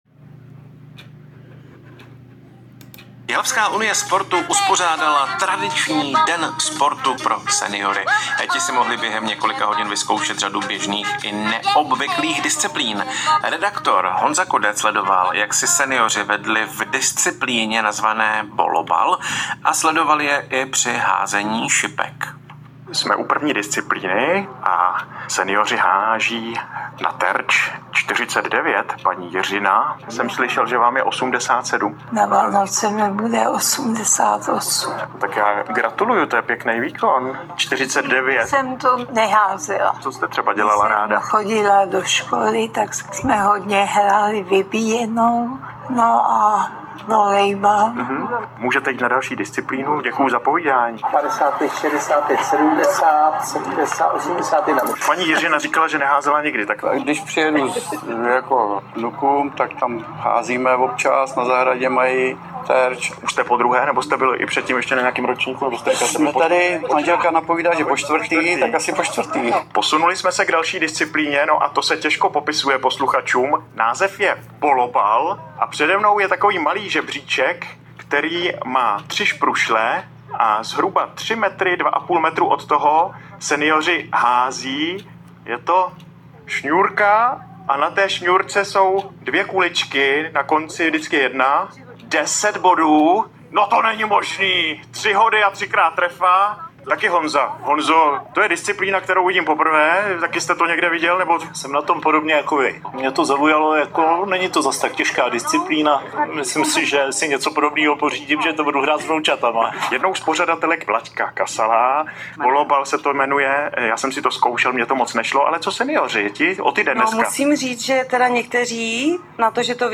Mediálně akci podpořil Český rozhlas (vstup přímo z akce si můžete poslechnout níže) a Jihlavské listy.